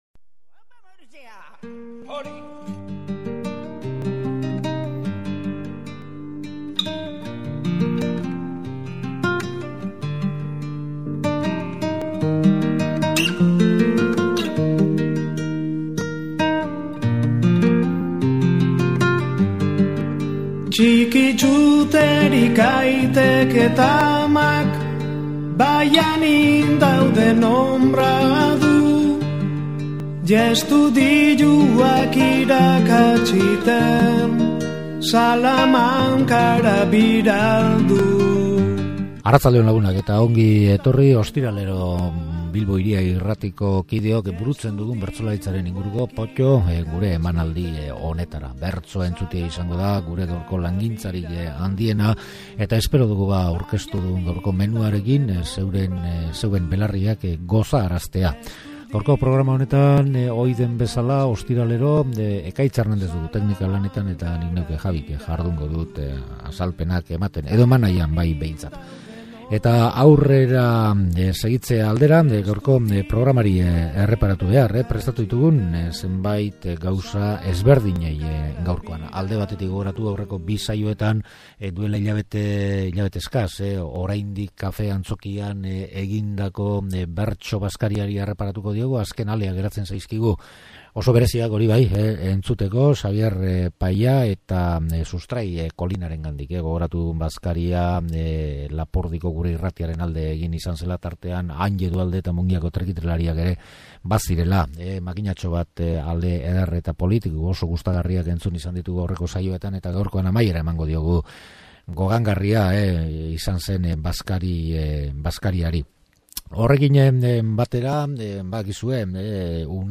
Kafe Antzokian egin “gure irratia”ren aldeko saioko azken txanpa entzun ahal izango dugu